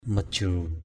/mə-ʥru:/ majru mj~% [Cam M] (d.) nhà thương, bệnh viện = hospital = hospital. sang majru s/ mj~% nhà thương, bệnh viện = hospital.